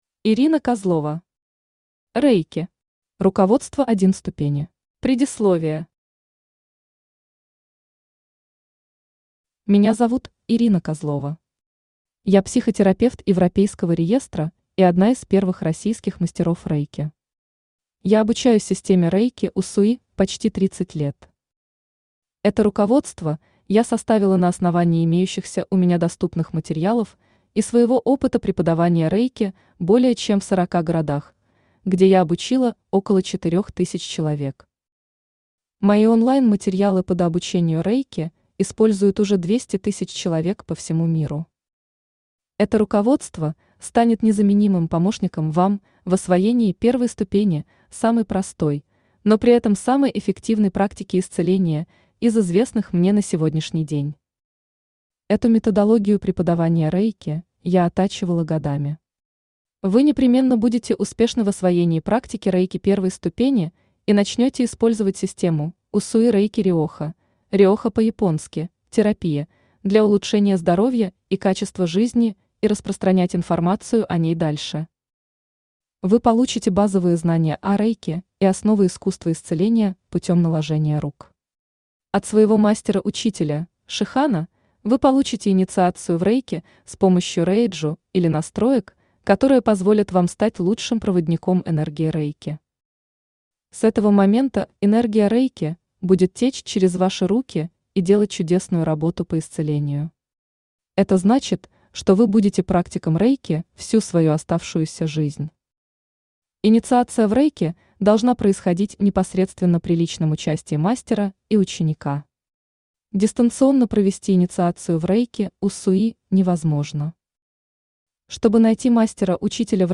Аудиокнига Рэйки. Руководство 1 ступени | Библиотека аудиокниг
Читает аудиокнигу Авточтец ЛитРес.